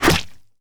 bullet_impact_mud_04.wav